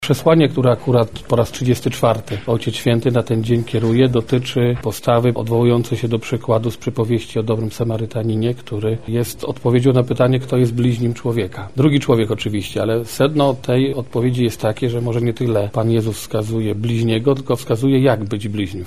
bp Adam Bab – mówi biskup Adam Bab.